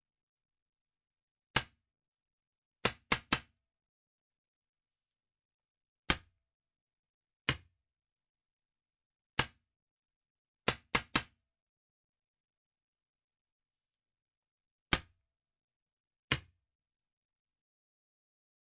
彩弹枪射击
描述：射击彩弹枪。